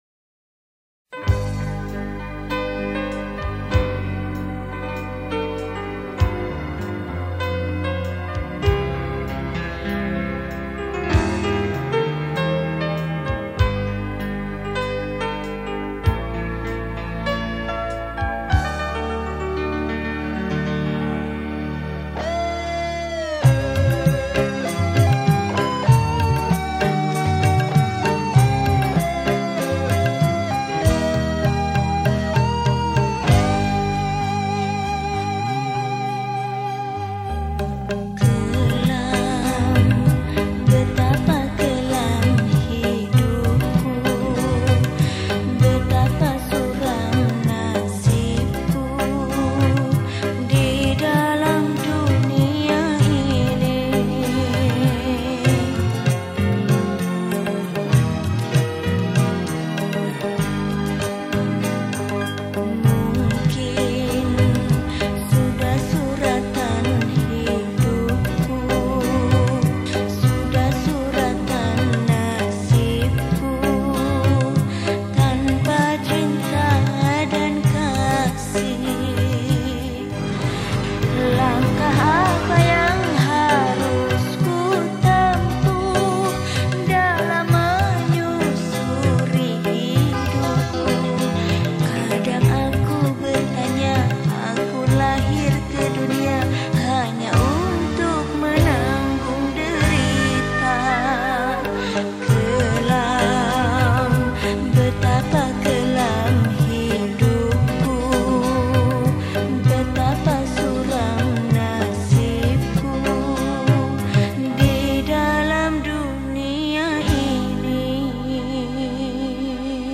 Professi                                    : Penyanyi
Genre Musik                            : Dangdut Original